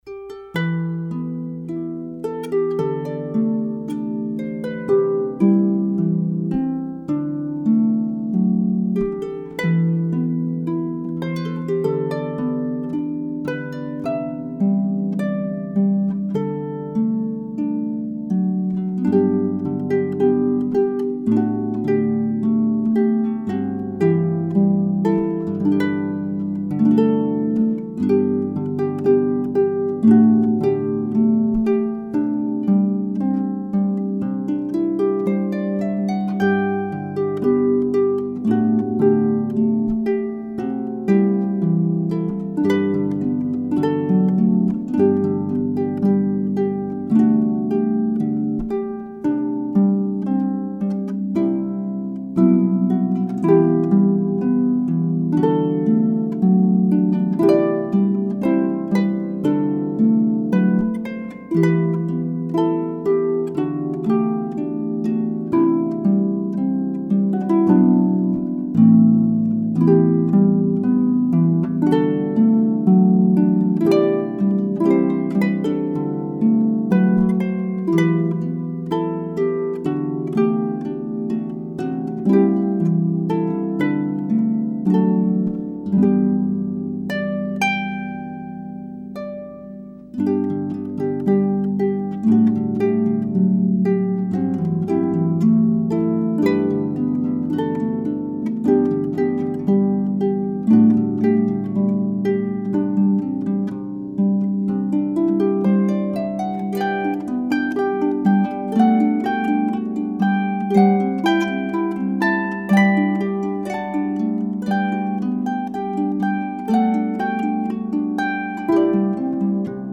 Harpist 2
harp2-8.mp3